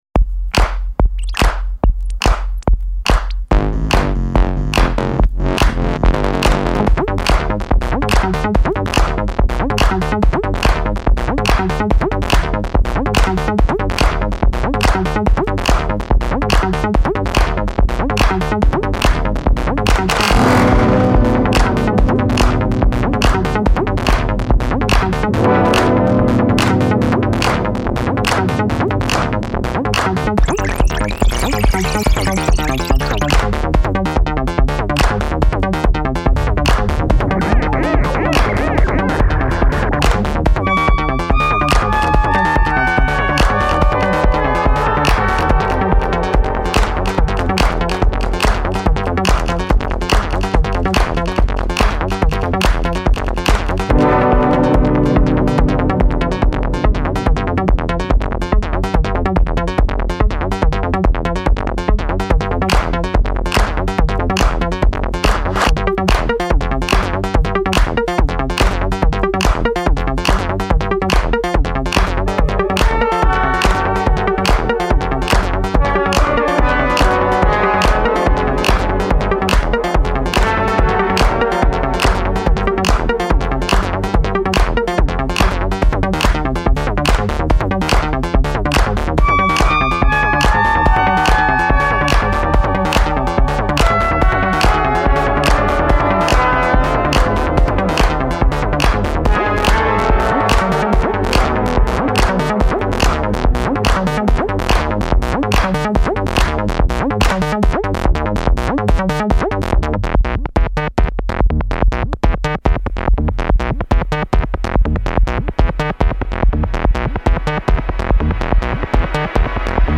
[ TECHNO / ACID ]